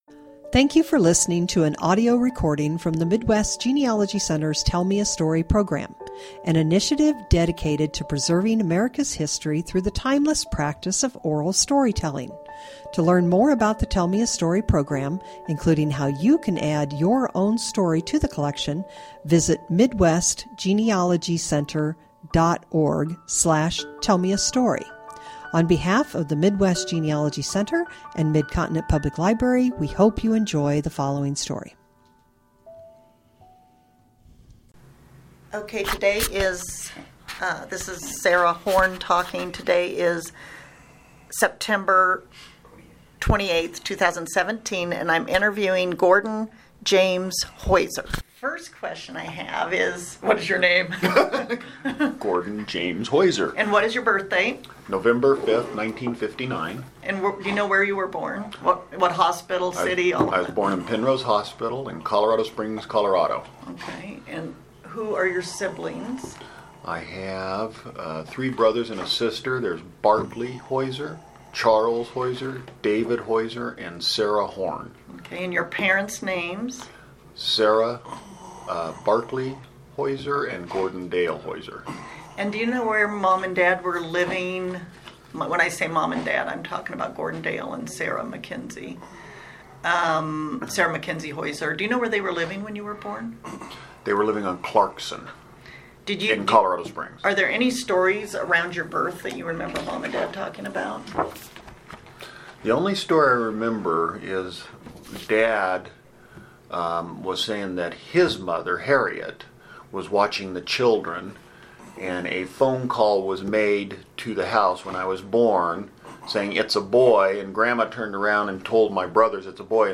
Oral History Genealogy Family History